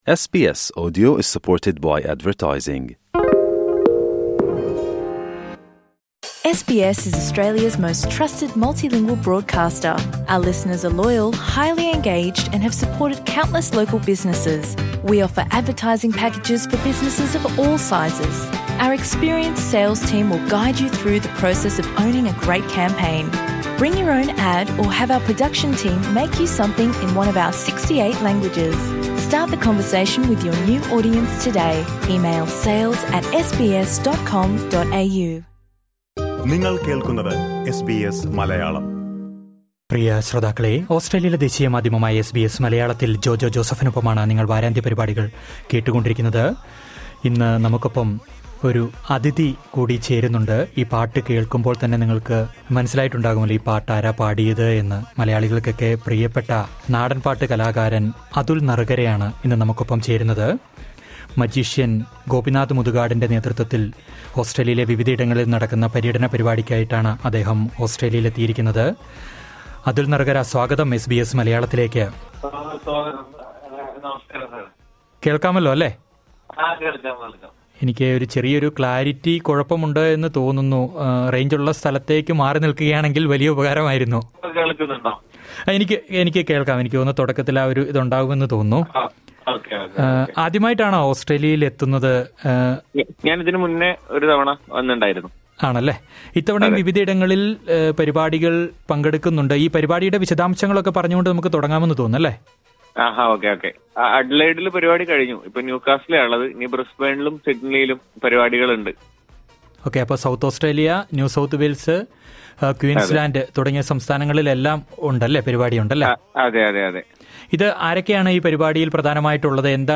നാടൻ പാട്ടിൻറെ ഈണങ്ങളും വിശേഷങ്ങളുമായി ഗായകൻ അതുൽ നറുകര.